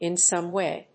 アクセント(in) sóme wày (or óther)